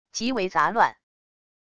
极为杂乱wav音频